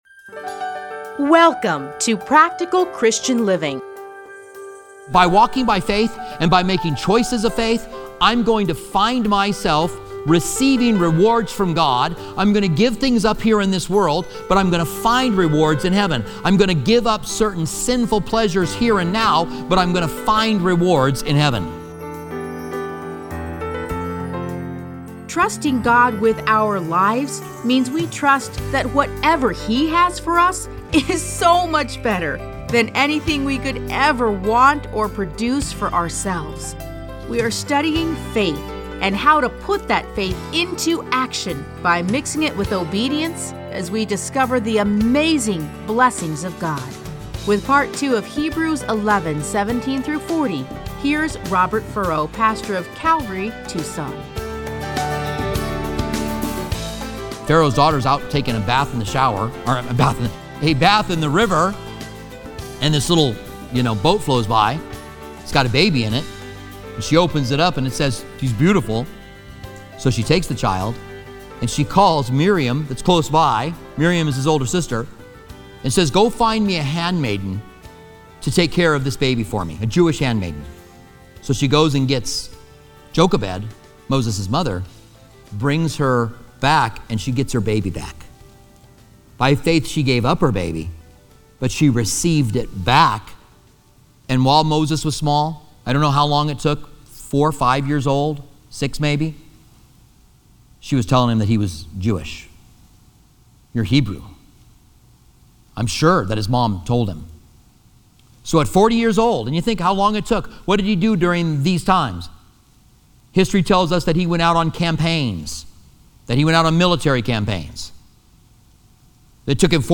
Listen to a teaching from Hebrews 11:17-40.